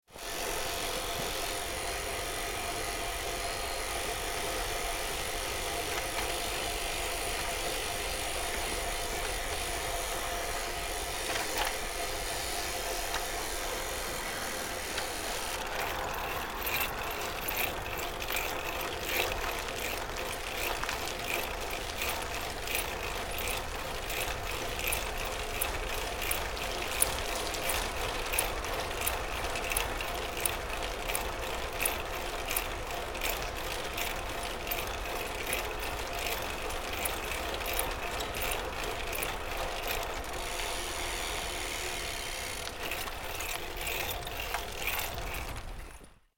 دانلود آهنگ دوچرخه 9 از افکت صوتی حمل و نقل
دانلود صدای دوچرخه 9 از ساعد نیوز با لینک مستقیم و کیفیت بالا
جلوه های صوتی